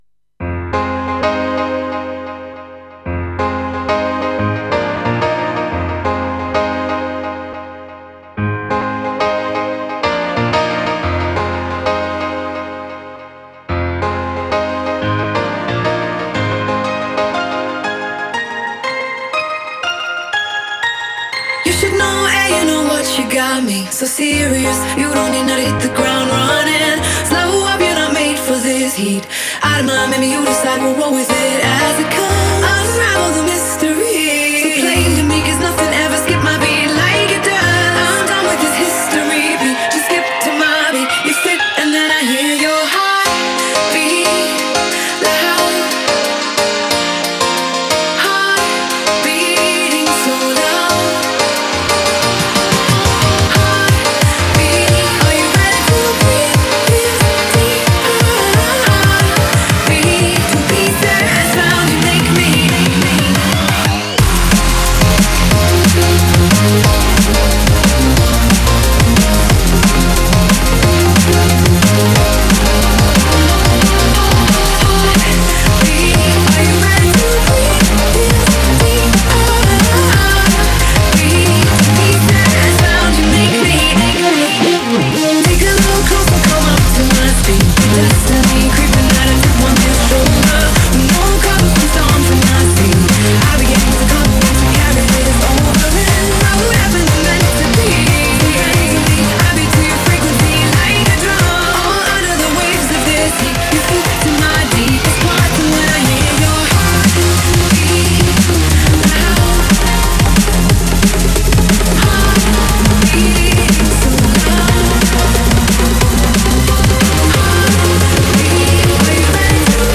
Drum&Bass